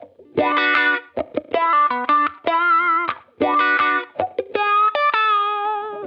Index of /musicradar/sampled-funk-soul-samples/79bpm/Guitar
SSF_StratGuitarProc1_79B.wav